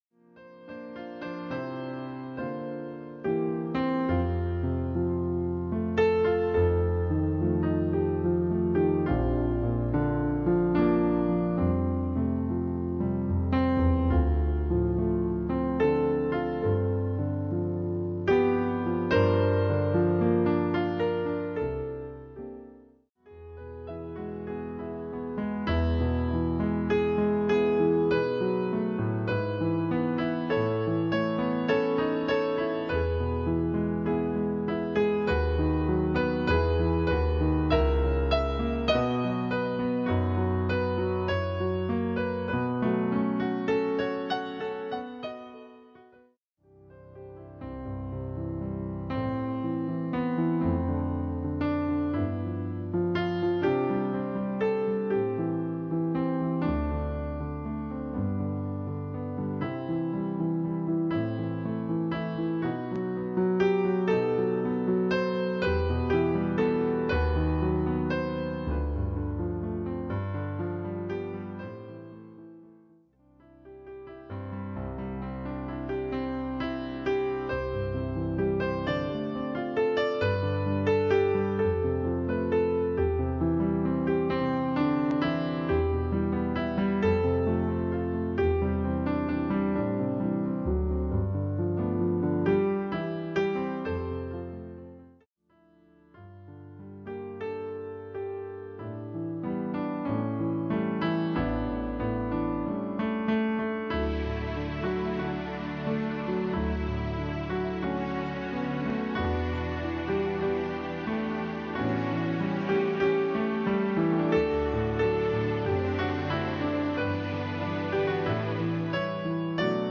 Pianist
inspirational piano performances